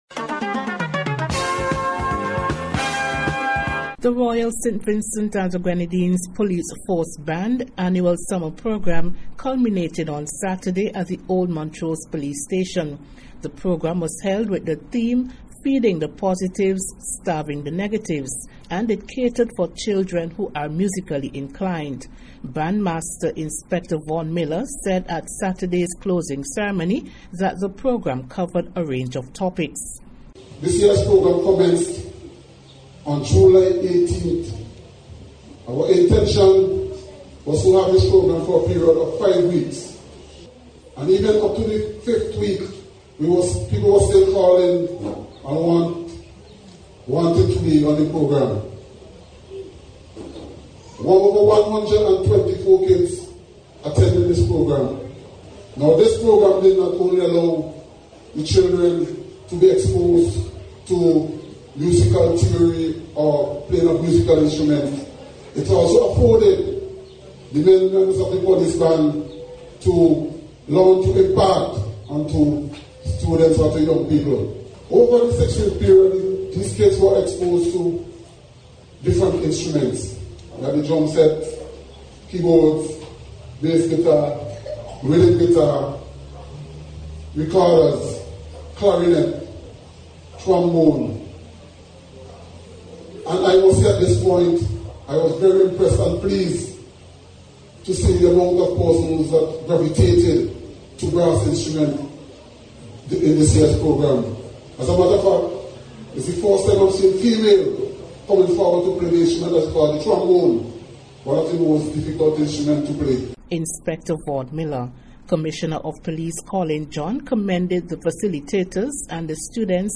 Here’s more in today’s Special Report.